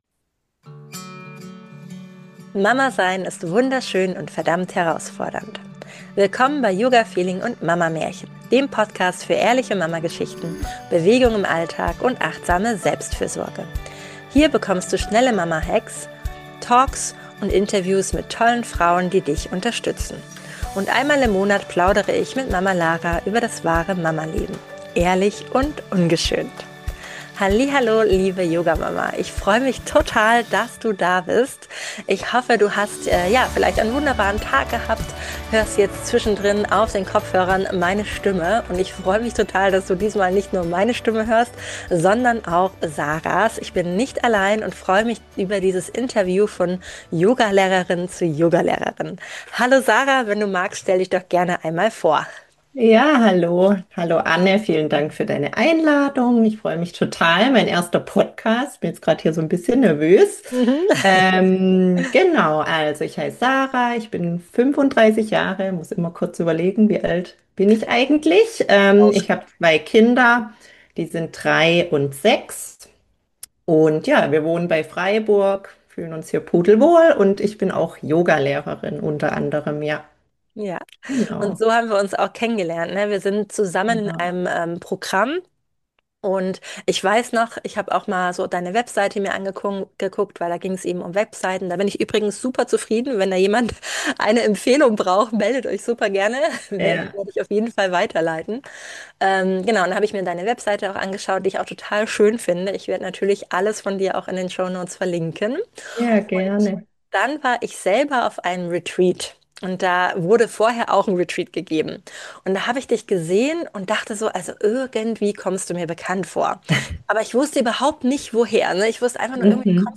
Zwei Yogalehrerinnen unter sich